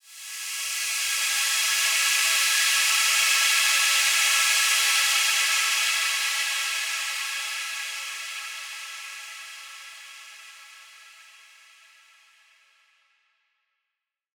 SaS_HiFilterPad04-A.wav